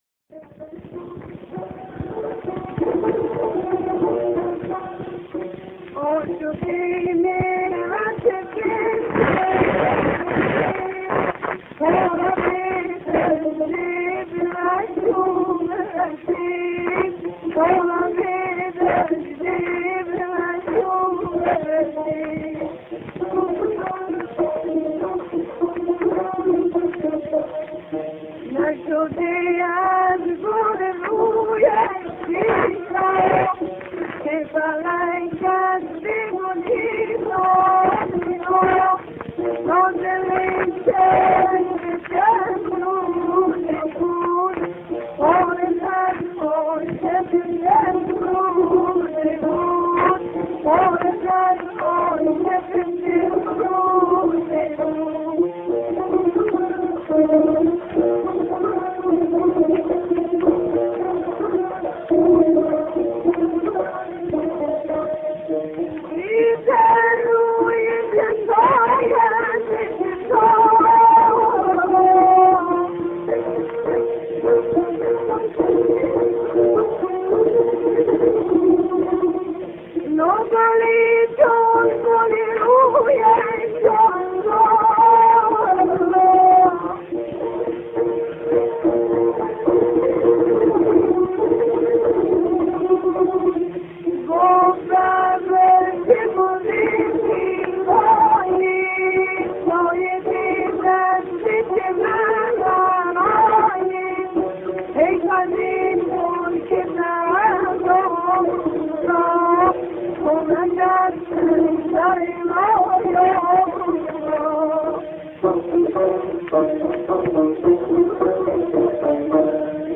دستگاه همایون